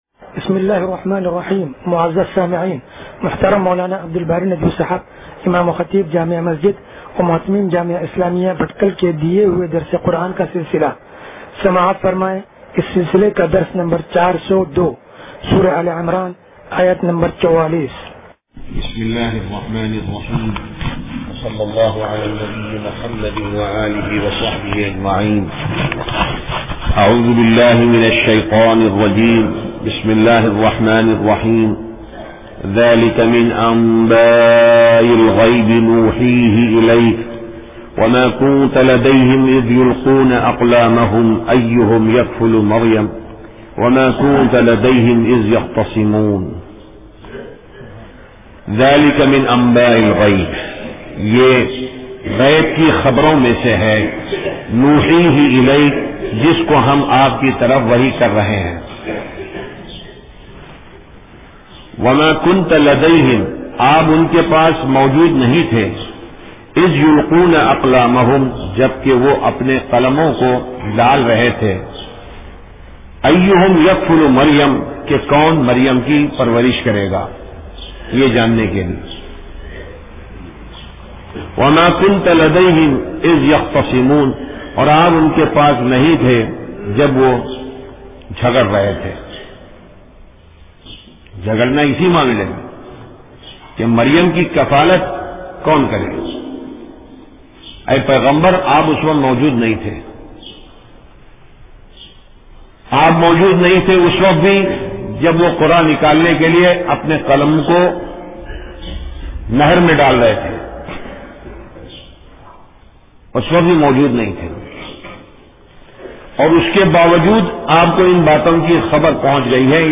درس قرآن نمبر 0402